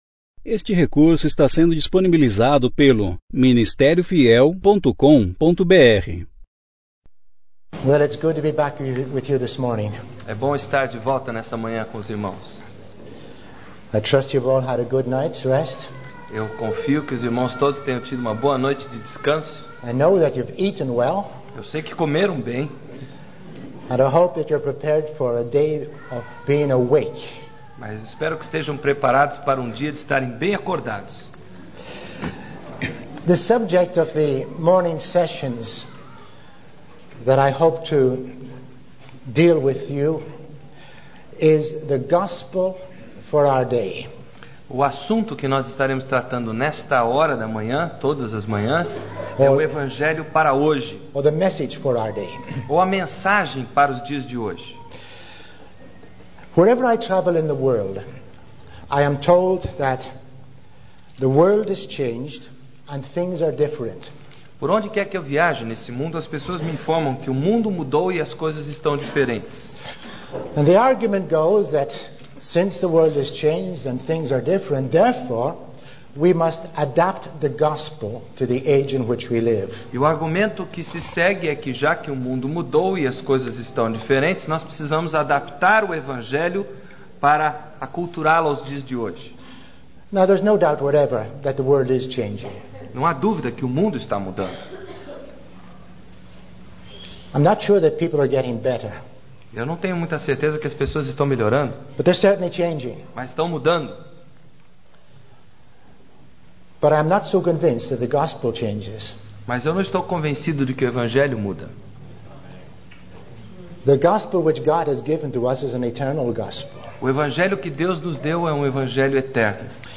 7ª Conferência Fiel para Pastores e Líderes – Brasil - Ministério Fiel
Palestra 1